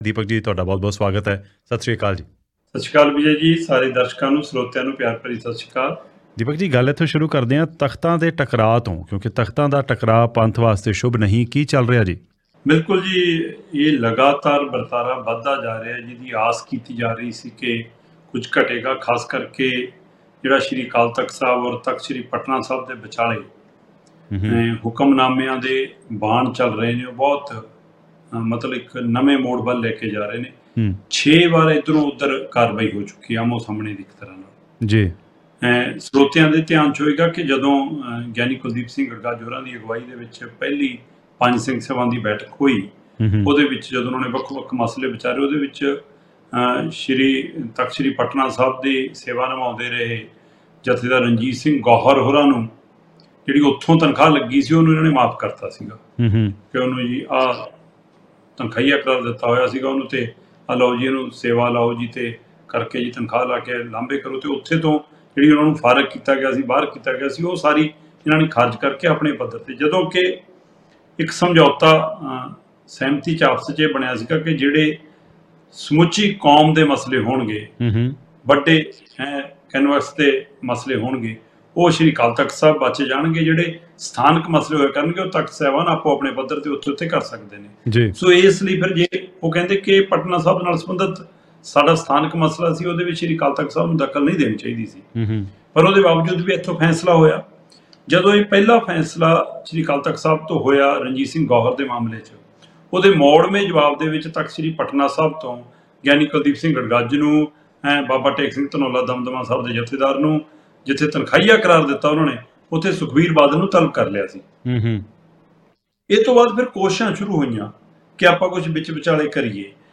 In today’s bulletin, we dive into some of Punjab’s most pressing issues – from troubling divisions among Sikh Takhat,…